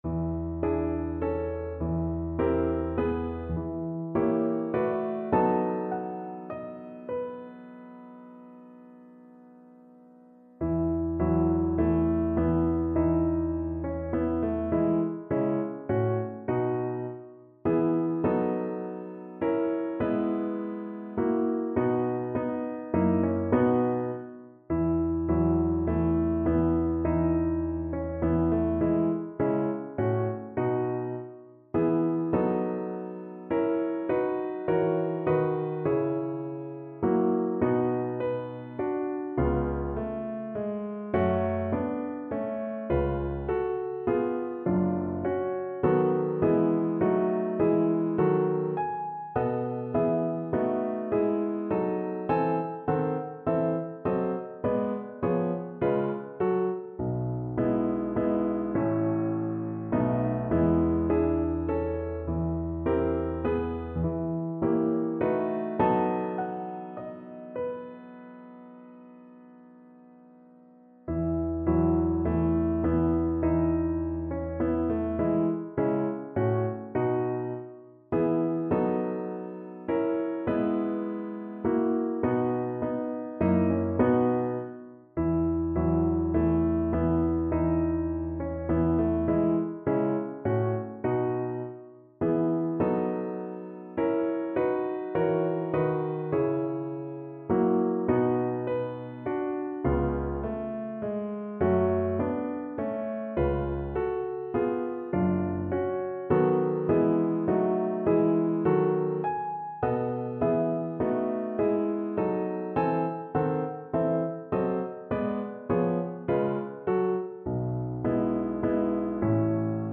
6/8 (View more 6/8 Music)
= 70 Andante
Classical (View more Classical Voice Music)